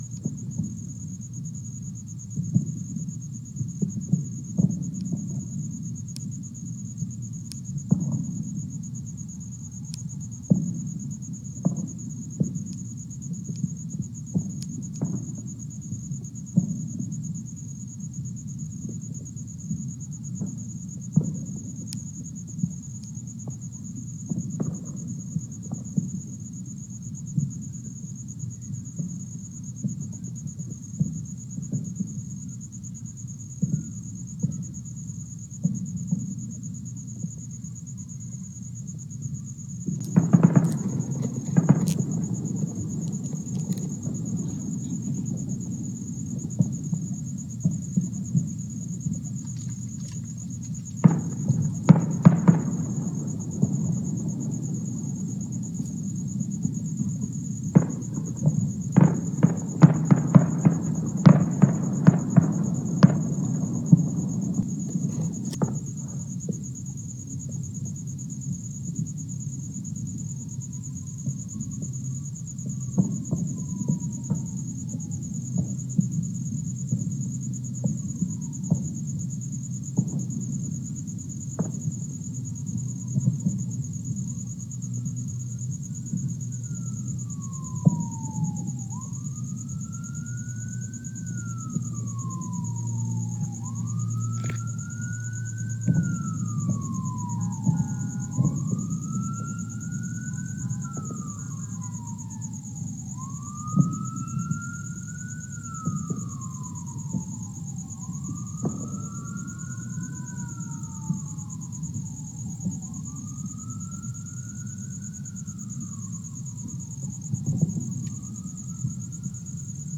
Here’s the audio off of a video I tried taking with my iPhone a while ago.
Note that there aren’t any public displays of fireworks within at least ten miles of us – everything you hear is being shot off by locals.